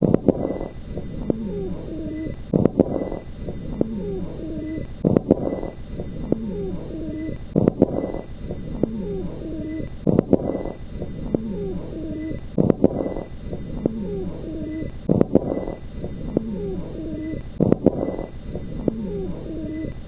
HENGITYSÄÄNET
Ensimmäinen näyte on taltioitu rintakehältä keskilinjan oikealta puolelta.
Erityisesti tietyltä alueelta kuuluva rahina viittaa tiivistymään oikean keuhkon ylälohkoissa.